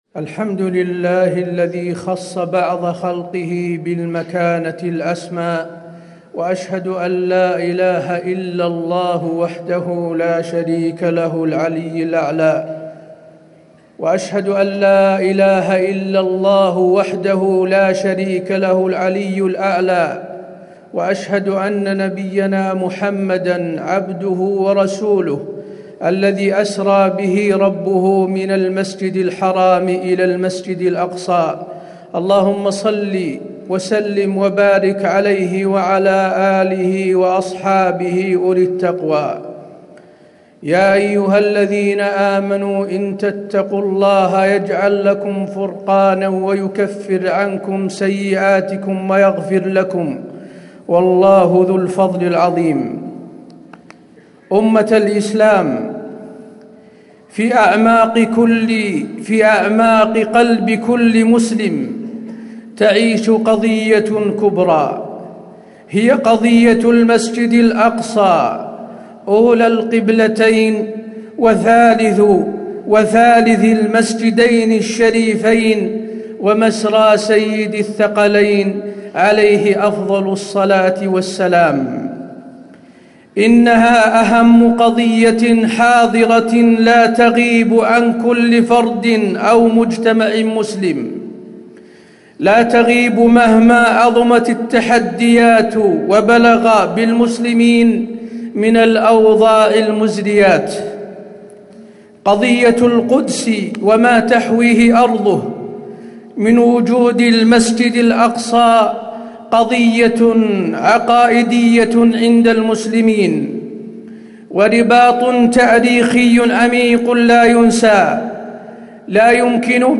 تاريخ النشر ٢٧ ربيع الأول ١٤٣٩ هـ المكان: المسجد النبوي الشيخ: فضيلة الشيخ د. حسين بن عبدالعزيز آل الشيخ فضيلة الشيخ د. حسين بن عبدالعزيز آل الشيخ قضية فلسطين إسلامية